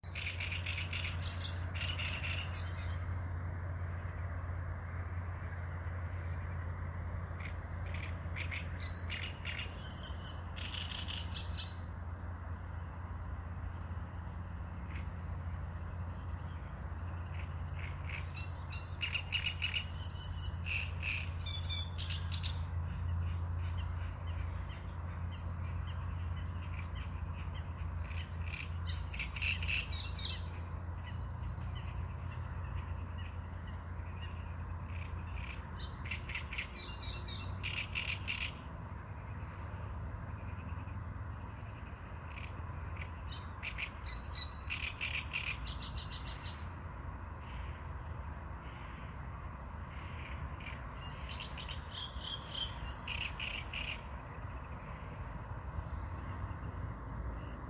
Niedru strazds, Acrocephalus arundinaceus
Administratīvā teritorijaRīga
StatussDzied ligzdošanai piemērotā biotopā (D)